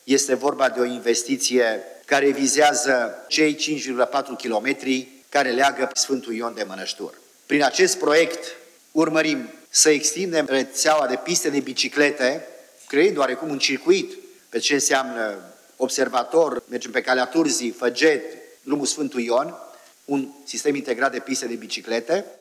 Odată cu realizarea pistelor de biciclete de pe drumul Sfântul Ion, primarul Emil Boc arată că municipiul va avea un un inel complet, care va conecta cartierele Zorilor și Mănăștur de zona pădurii Făget.
Boc-modernizare-drum-sf-ion.wav